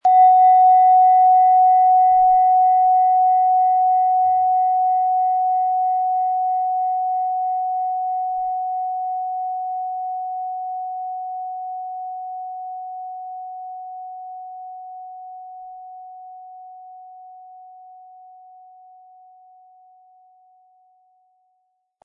Japanische Klangschale Solfeggio 741 Hz - Erwachen
Die japanische Klangschale mit 741 Hertz entfaltet einen hellen, klaren Ton, der den Geist weitet und innere Klarheit stärkt.
Sorgfältig gearbeitete japanische Klangschalen mit 741 Hz zeichnen sich durch Reinheit und Fokussierung im Klang aus. Der Ton klingt klar und fördert Wachheit sowie geistige Präsenz.
MaterialBronze